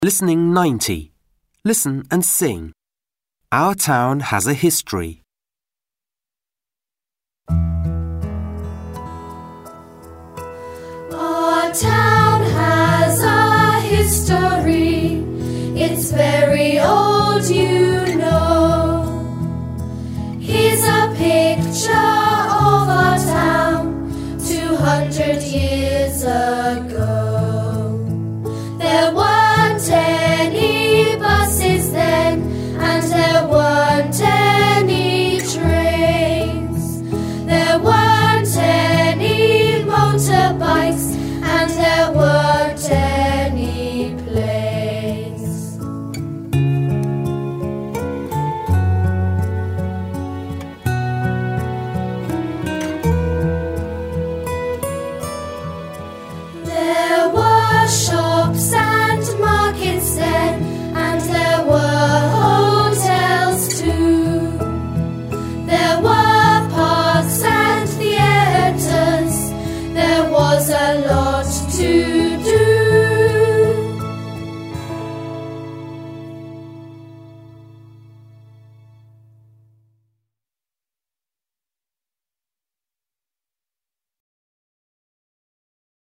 3) Listen and sing